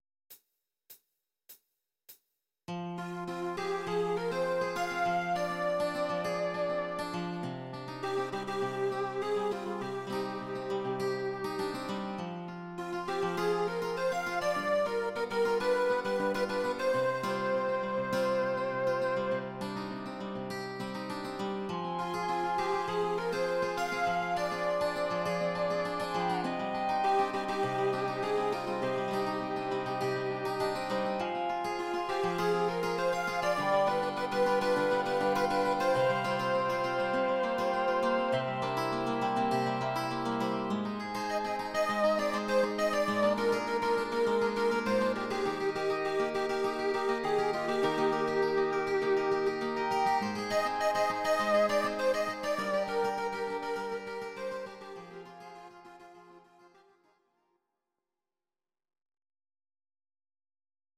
These are MP3 versions of our MIDI file catalogue.
Please note: no vocals and no karaoke included.